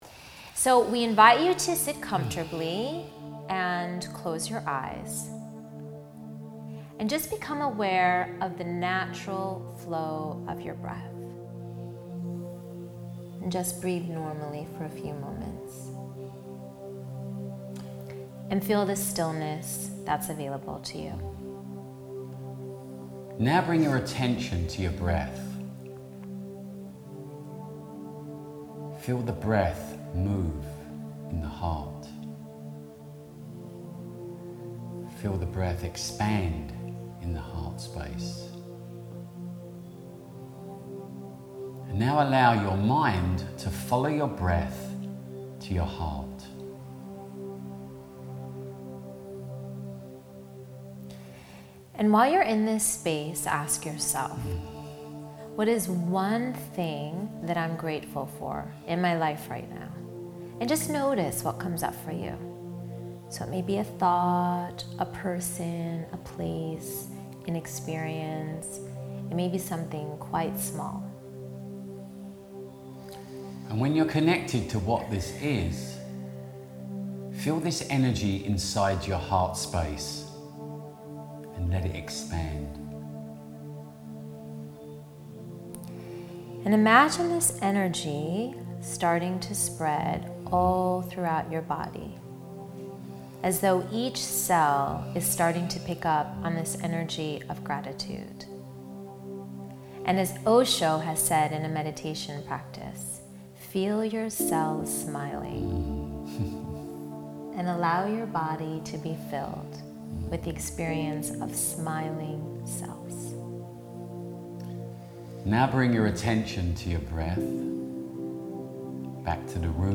The Echoing Joy audio gratitude meditation